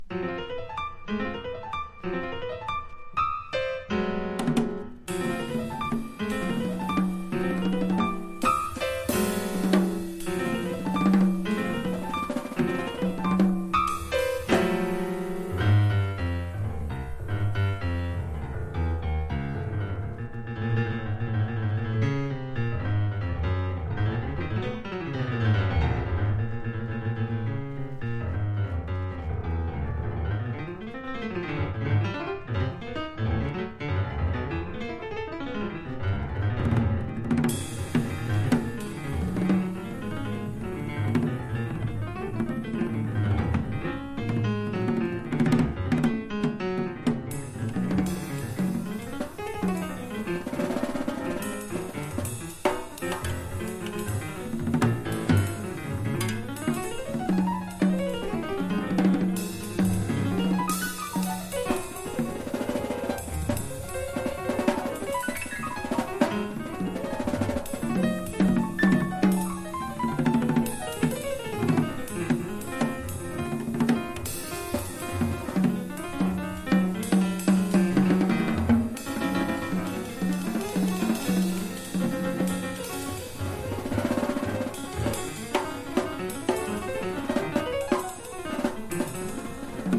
スタジオ・レコーディングされたアルバムで
# 和ジャズ# FREE / SPIRITUAL